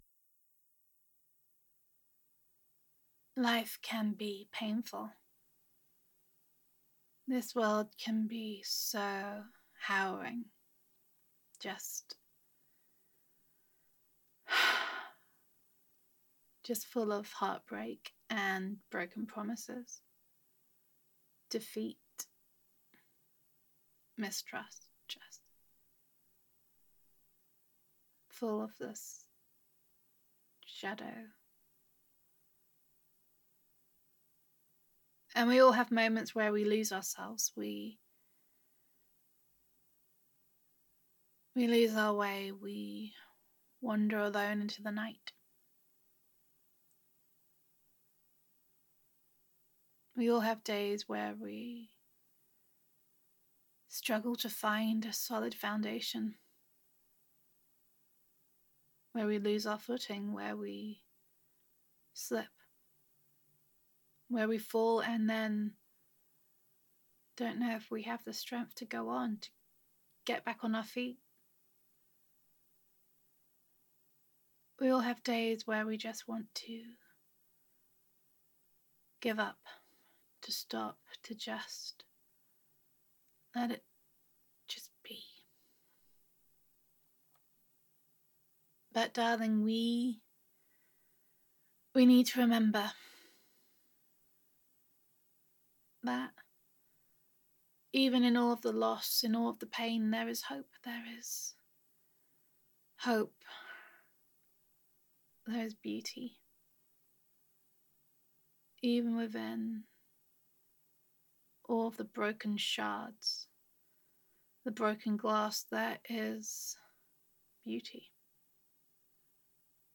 [F4A] Someone’s Someone
[Comfort and Reassurance]